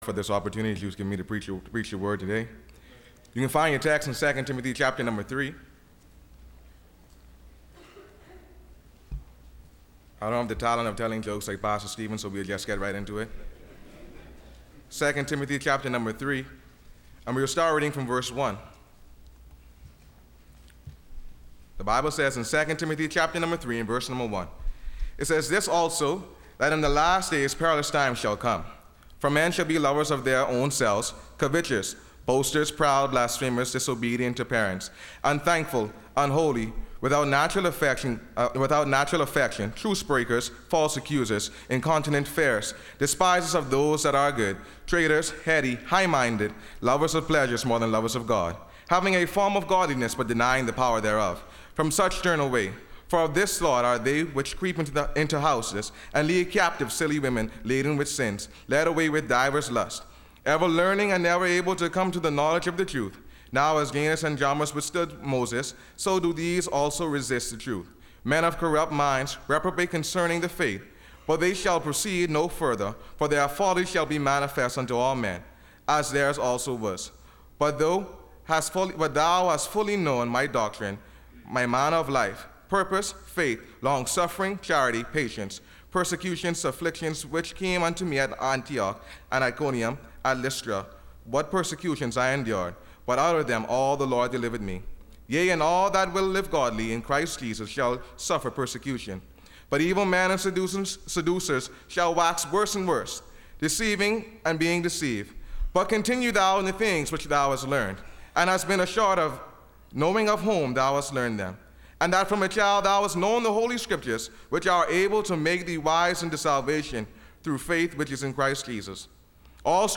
Listen to Message
Service Type: Bible Conference